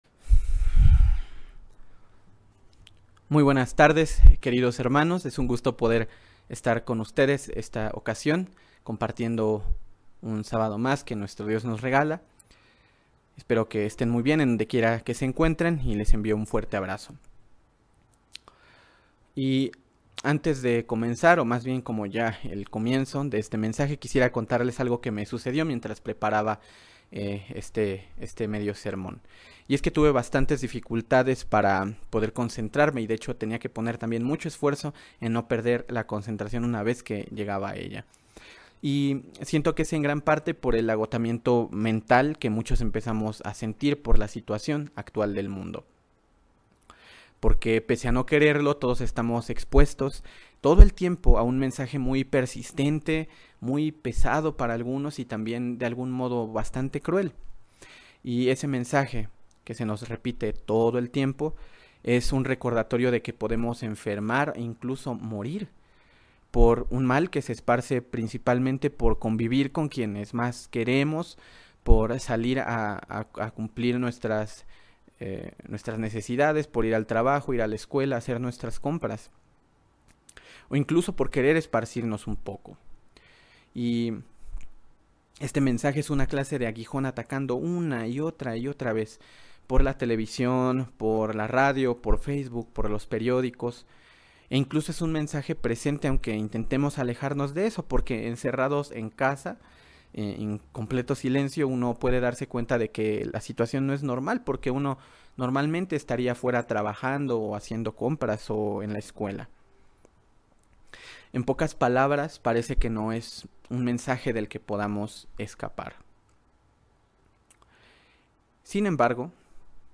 Que el amor de las personas se enfríe es un síntoma de los tiempos del fin, y podemos ver que es un mal que se encuentra en proceso. Mensaje entregado el 26 de diciembre de 2020.